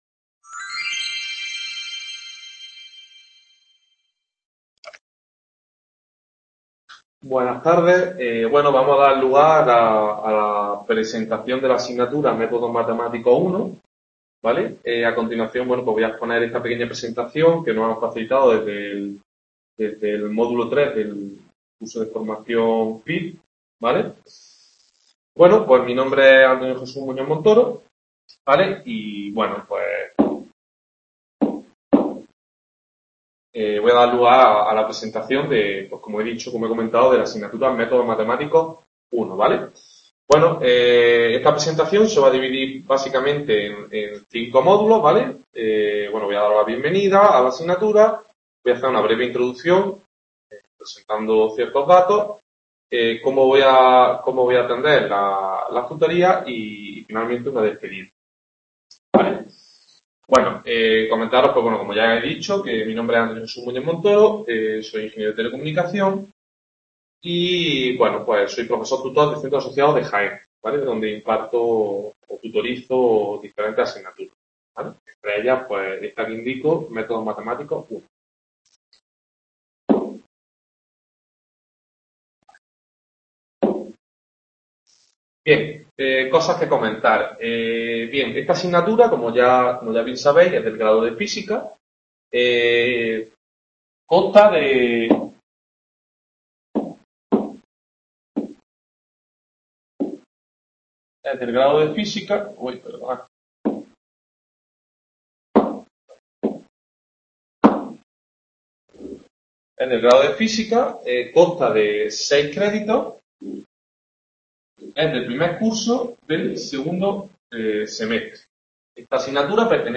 Video Clase